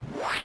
whoosh_down.mp3